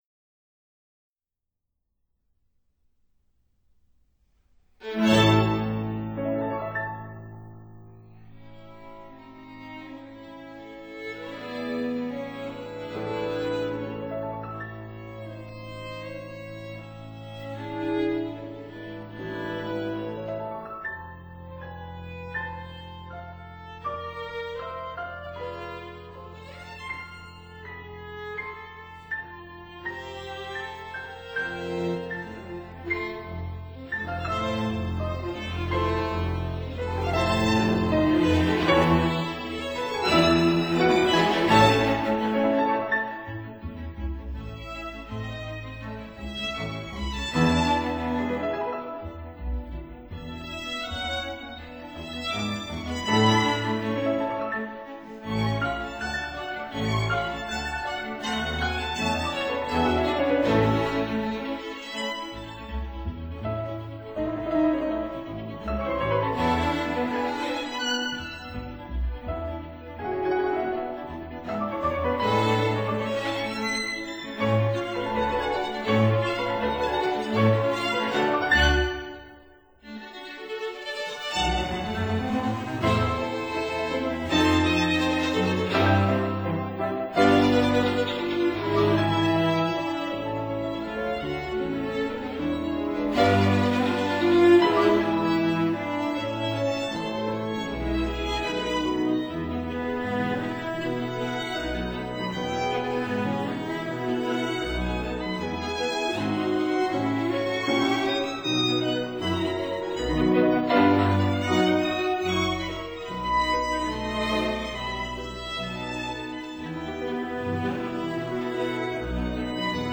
fortepiano
violin
cello